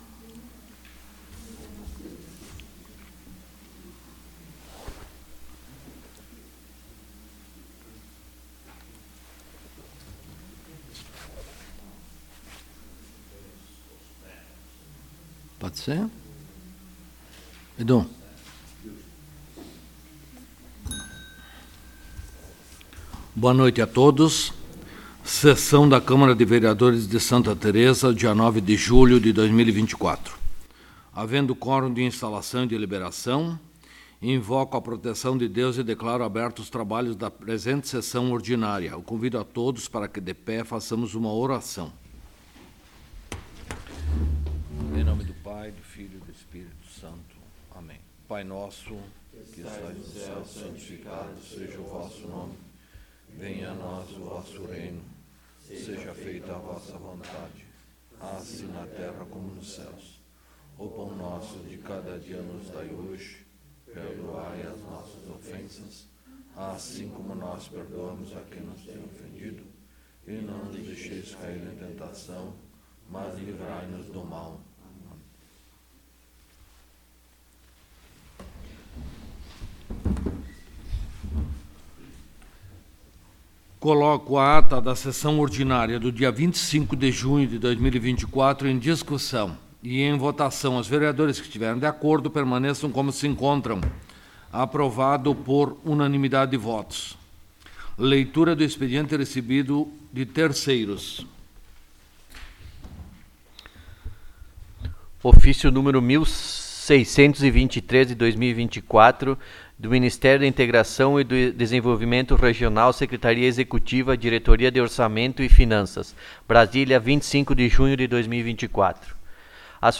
11° Sessão Ordinária de 2024